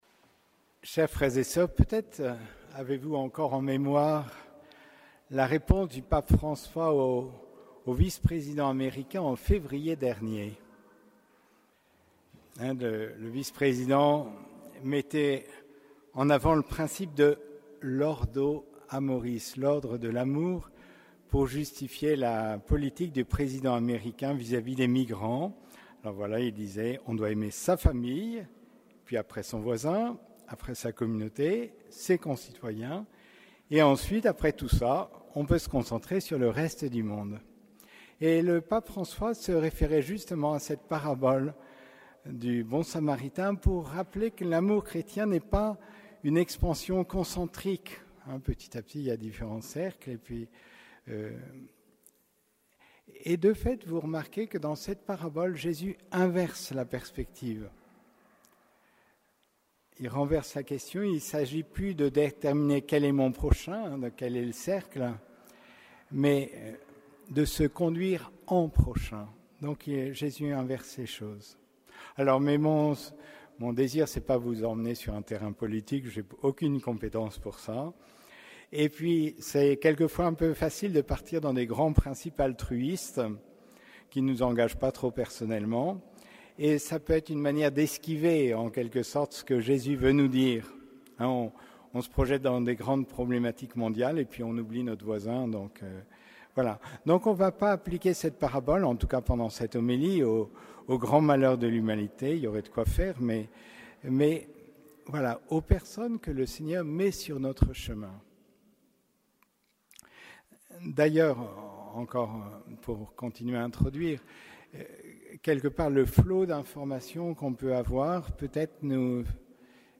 Homélie du troisième dimanche de Pâques